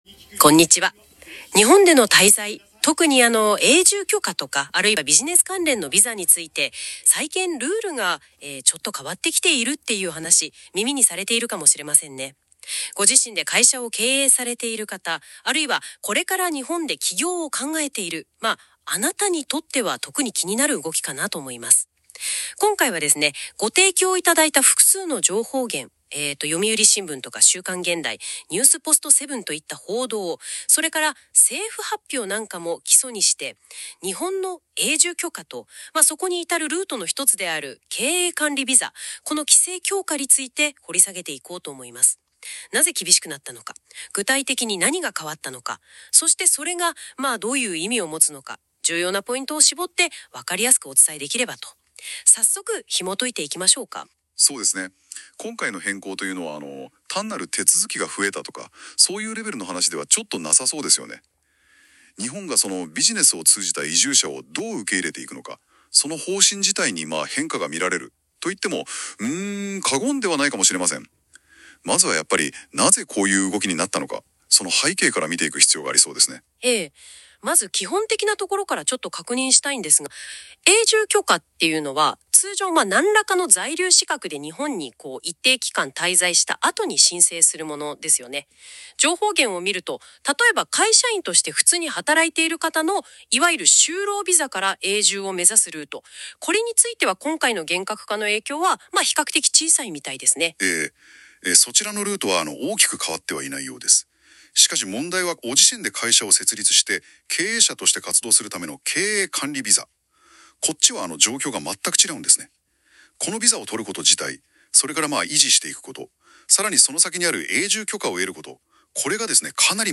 音声解説